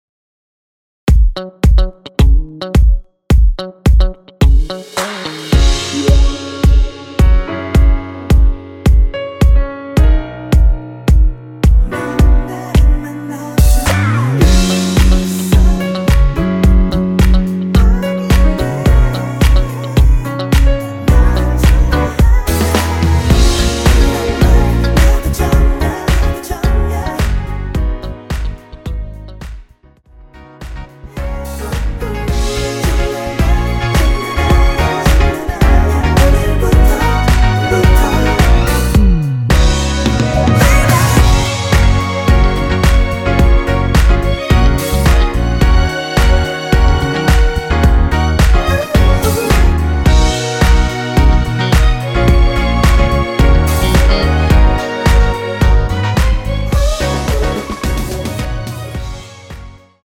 원키에서(+2)올린 코러스 포함된 MR입니다.(미리듣기 확인)
F#
앞부분30초, 뒷부분30초씩 편집해서 올려 드리고 있습니다.